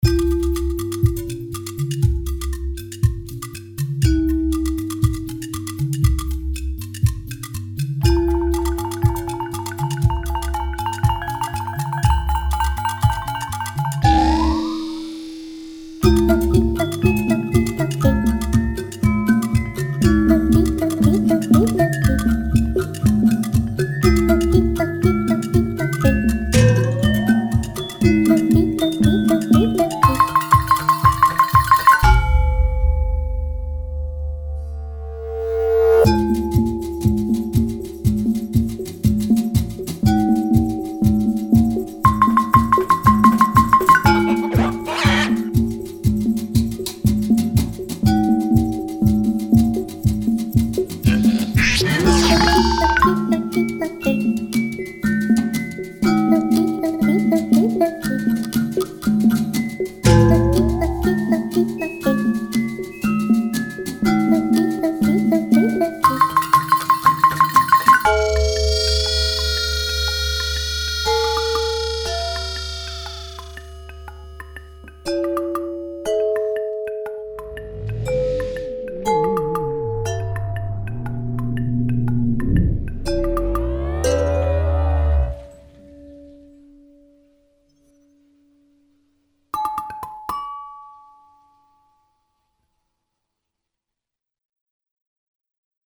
Génériques de début et de fin (01:34)
Poêles en cuivre Métallophone
Poêlon en cuivre Caisse claire
Bol en verre (joué au clavier MIDI) Vibraphone
Plats en terre cuite Xylophone
Marmite Basse
Couvercles en inox Cymbales splash, ride